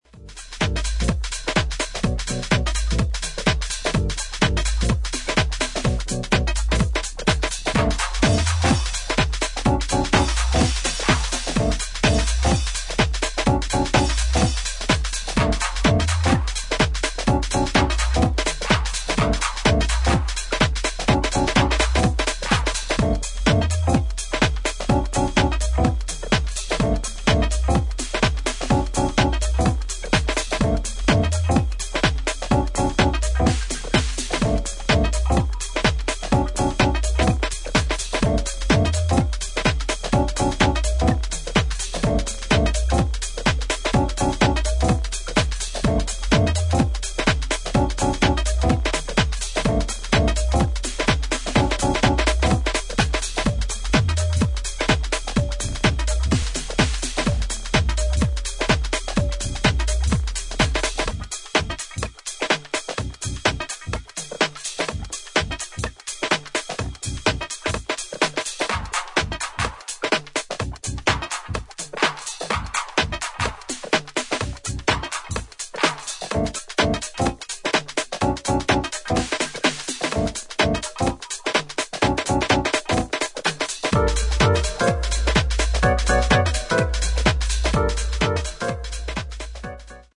ジャジーな要素をアーティストそれぞれが異なる解釈でフィットさせたディープハウス四曲を収録。